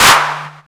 CLAP     4-L.wav